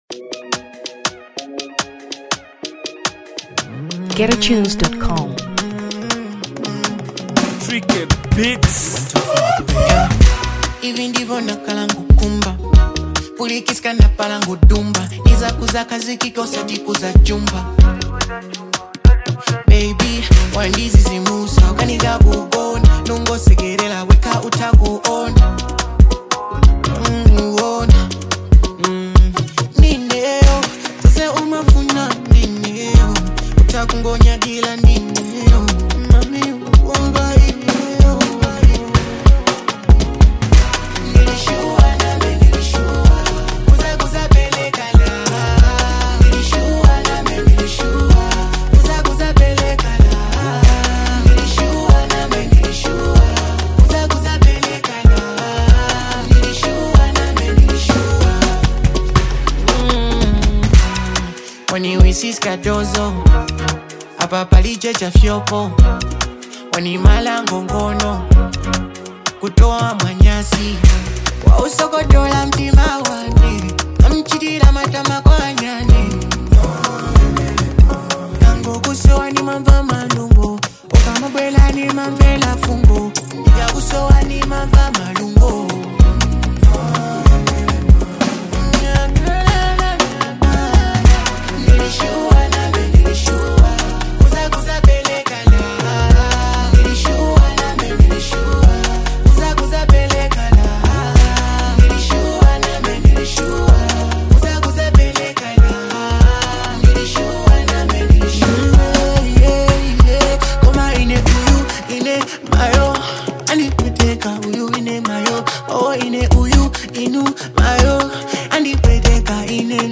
Afronyasa 2023 Malawi